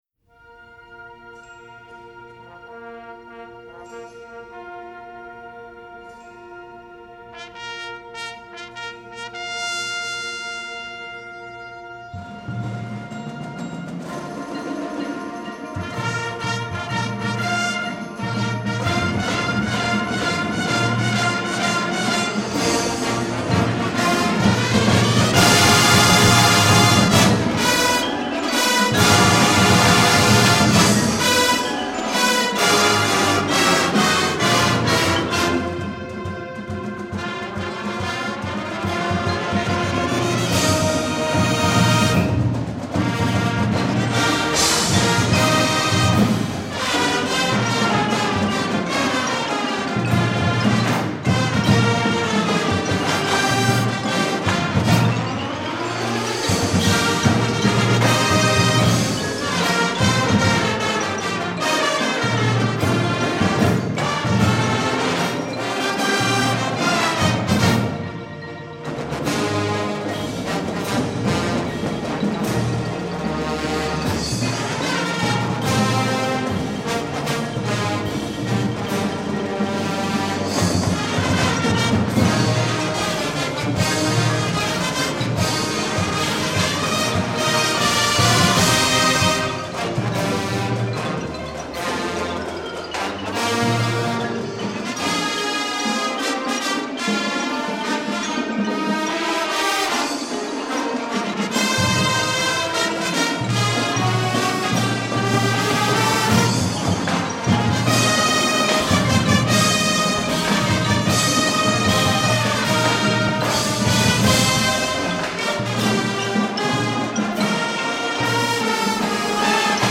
classical themed Marching Band Show